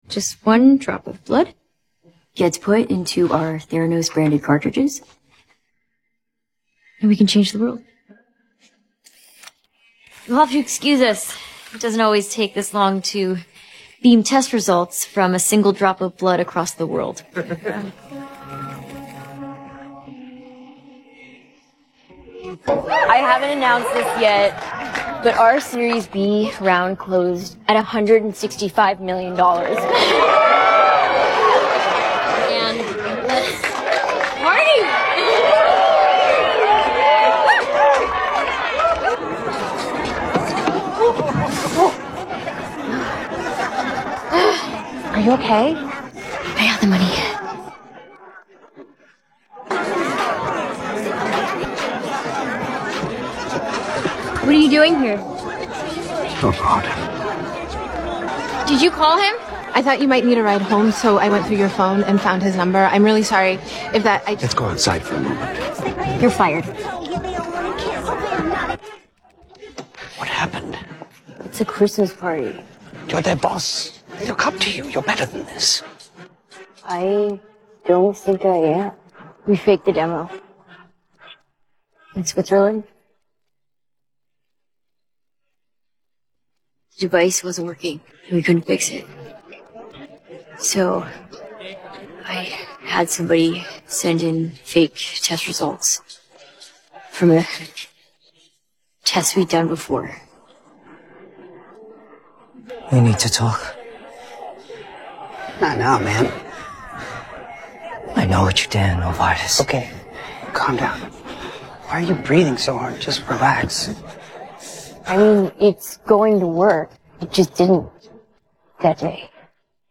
Голосовая часть